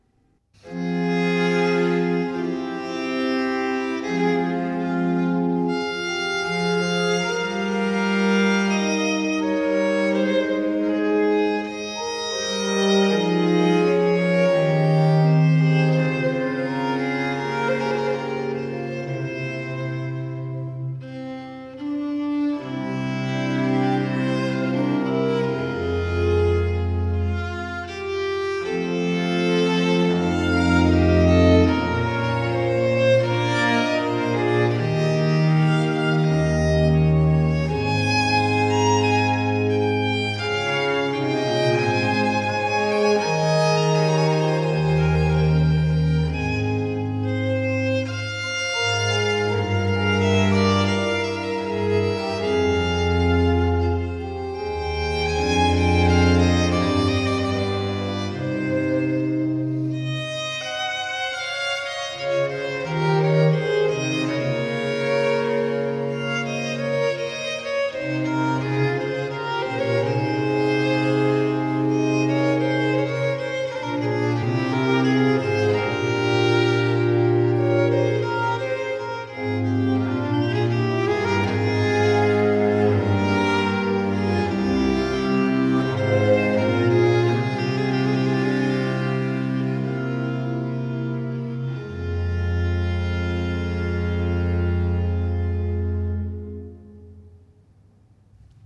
Largo